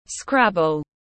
Trò xếp chữ tiếng anh gọi là scrabble, phiên âm tiếng anh đọc là /ˈskræb.əl/
Scrabble /ˈskræb.əl/